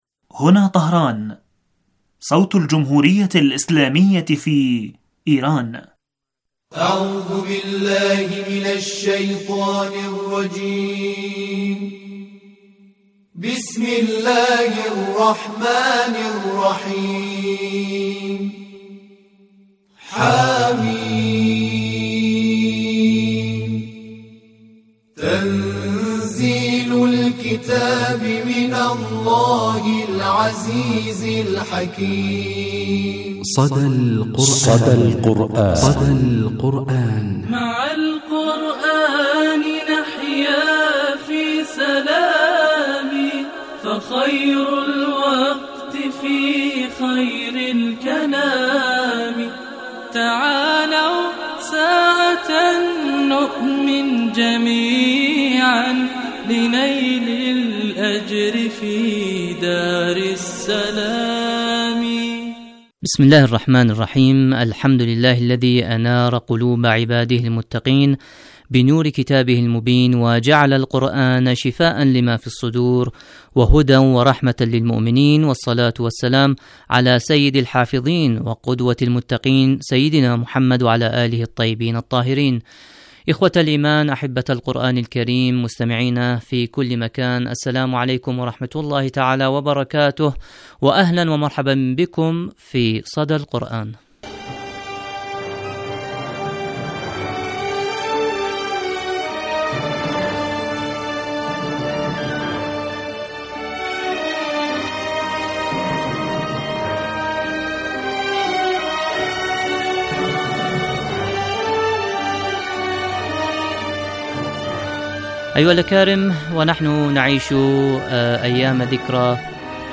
مجلة قرآنية أسبوعية تتناول النشاطات القرآنية ومشاهير القراء من الرعيل الأول ولقاءات وأسئلة قرآنية ومشاركات المستمعين وغيرها من الفقرات المنوعة.